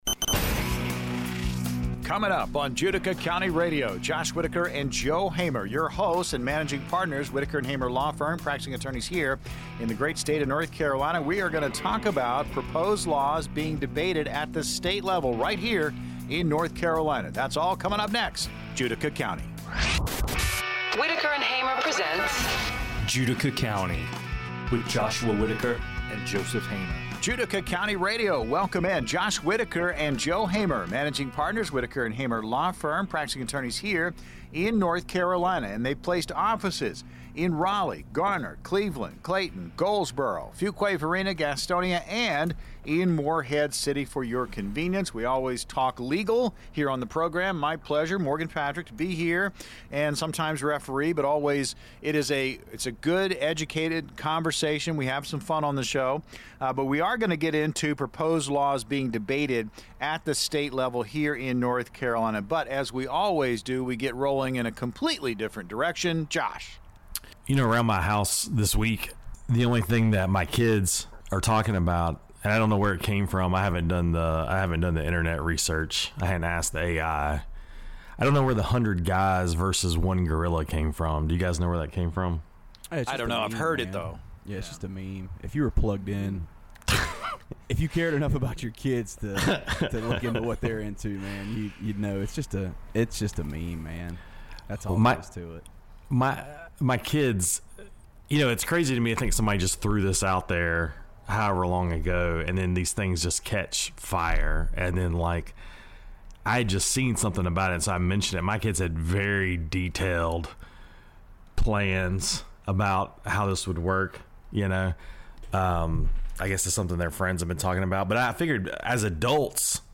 The conversation begins with a light-hearted discussion about a meme involving 100 guys versus a gorilla, transitioning into serious legal topics. The hosts emphasize the importance of understanding local laws and encourage listeners to engage with their representatives on issues that matter to them.